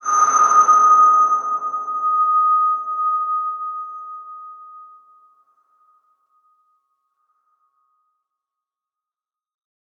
X_BasicBells-D#4-pp.wav